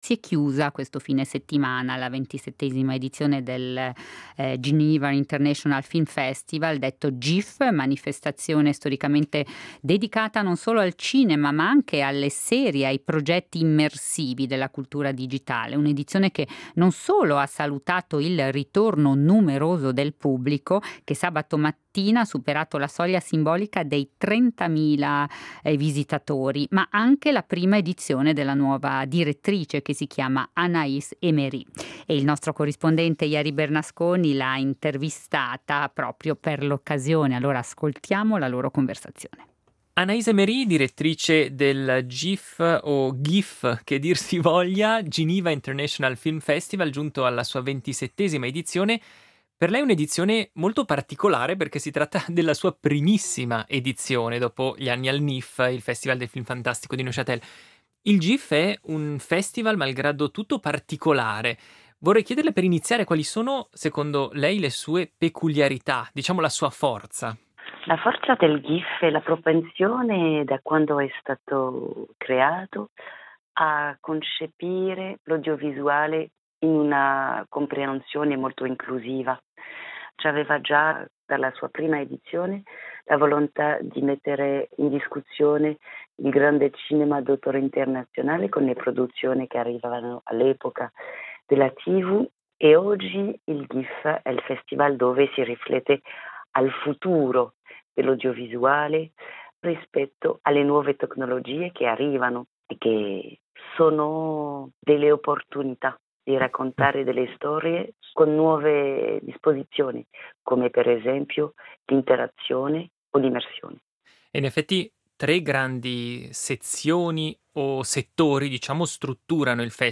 ha intervistato.